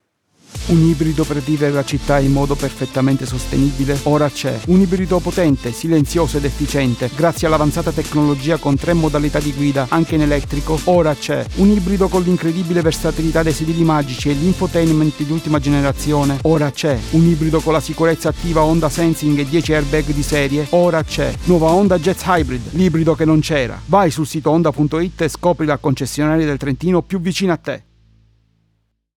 Italian speaker and voice artist, warm voice, young, middle, old, character voices, medical narration, e-learning, ads, commercial, audiobooks, IVR and phone system
Sprechprobe: Werbung (Muttersprache):
I have a warm voice and I can do a variety of voice delivery.
Usually I work with a CAD e100s condenser microphone, a Solid State Logic 2 audio interface but I have a tube amplifier if that kind of sound is needed.